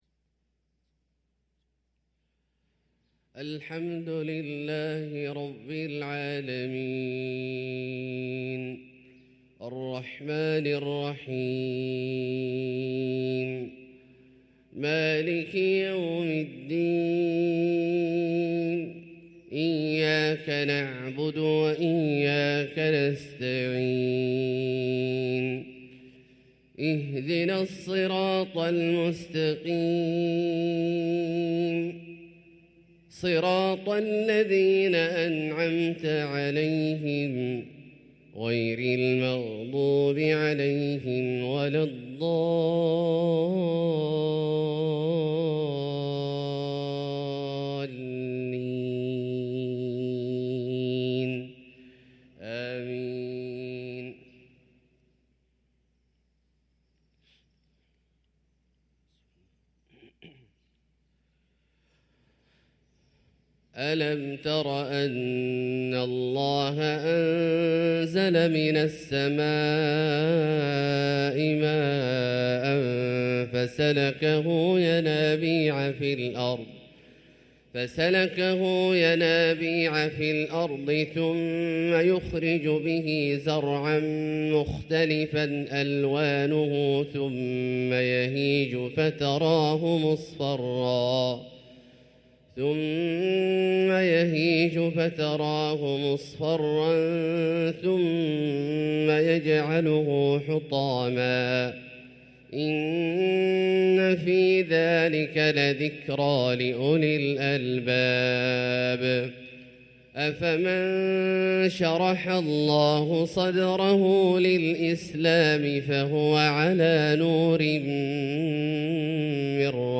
صلاة الفجر للقارئ عبدالله الجهني 26 ربيع الأول 1445 هـ
تِلَاوَات الْحَرَمَيْن .